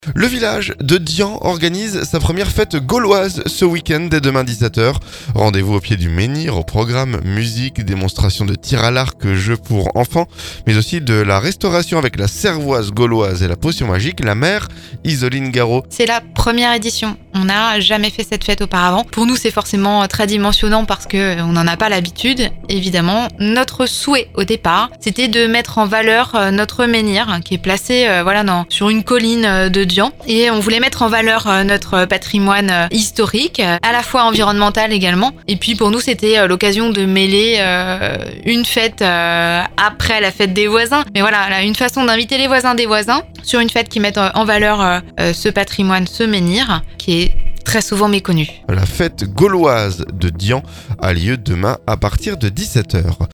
La maire Isoline Garreau à notre micro.